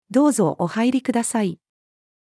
どうぞお入りください。-female.mp3